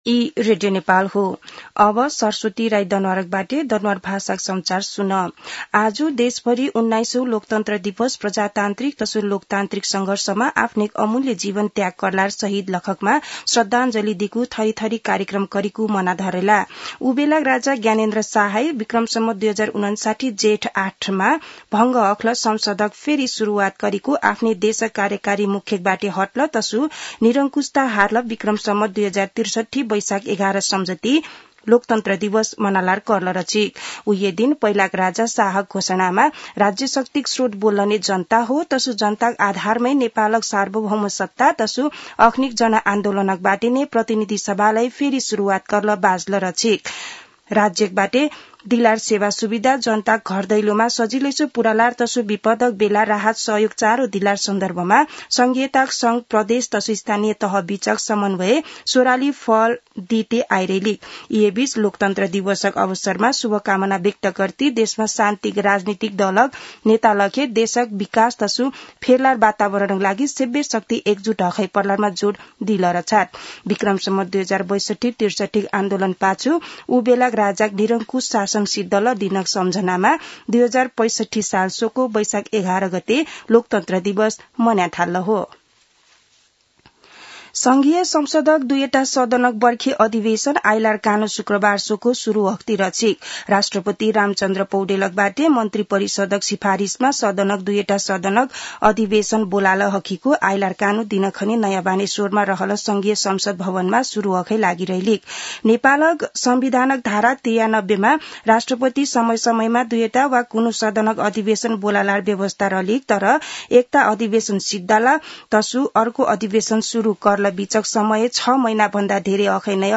दनुवार भाषामा समाचार : ११ वैशाख , २०८२
danuwar-news-1-10.mp3